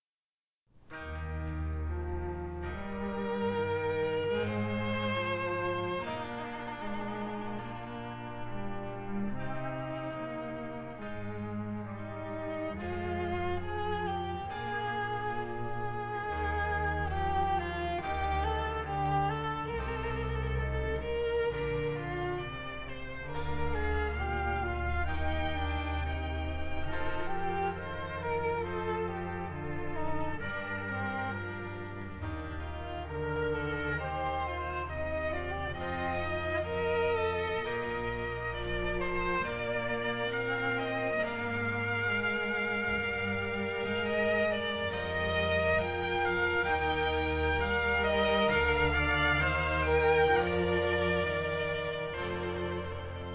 (viola da gamba)
1960 stereo recording made by